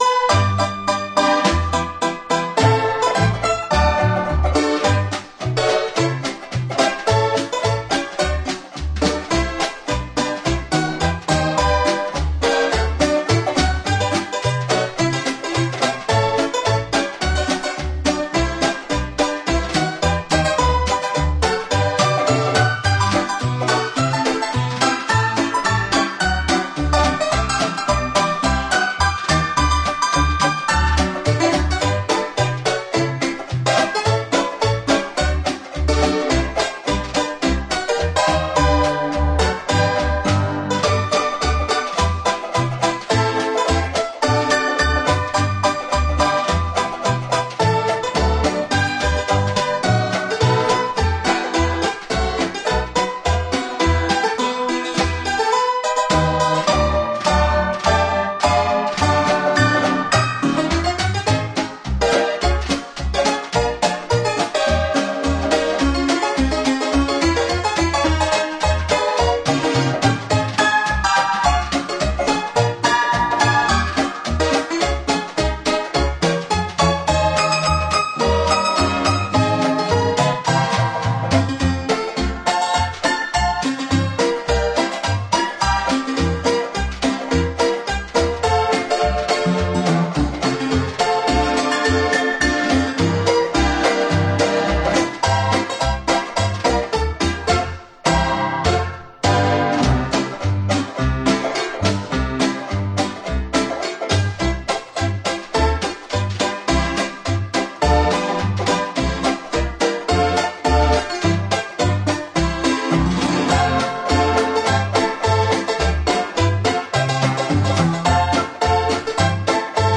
Жанр: Easy Listening, Dixieland, Ragtime, Swing